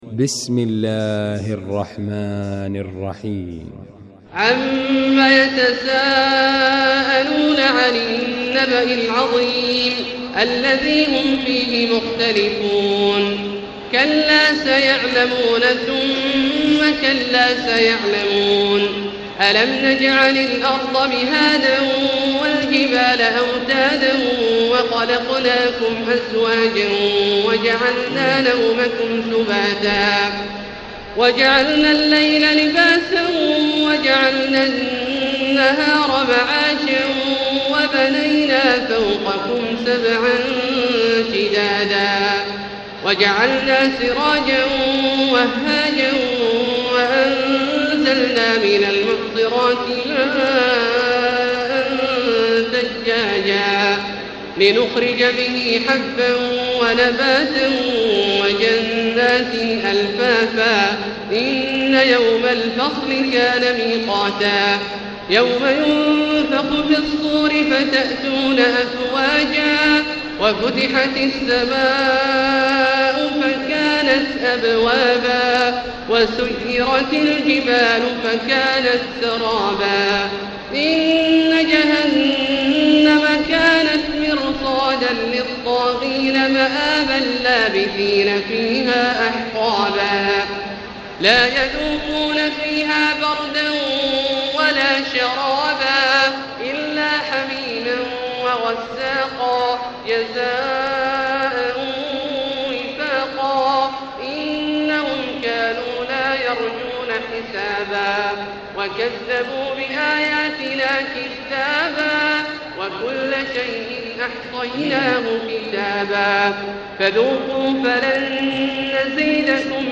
المكان: المسجد الحرام الشيخ: فضيلة الشيخ عبدالله الجهني فضيلة الشيخ عبدالله الجهني النبأ